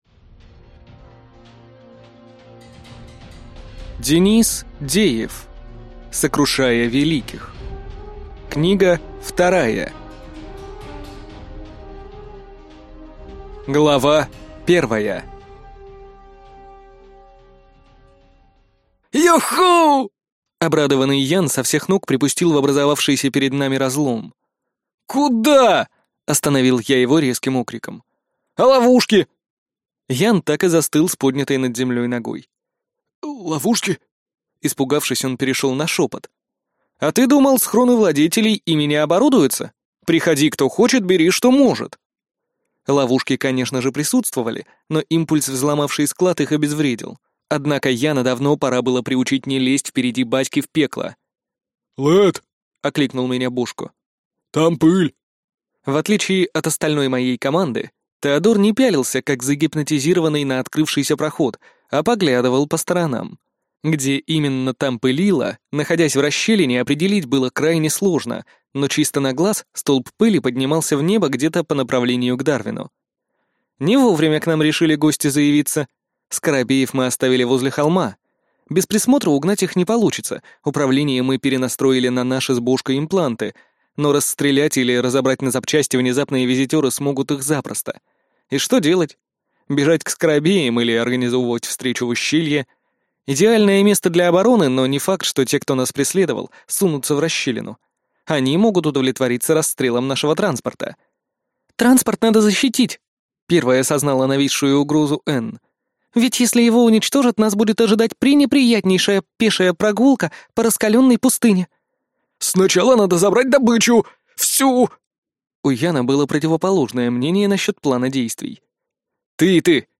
Аудиокнига Сокрушая великих. Книга 2 | Библиотека аудиокниг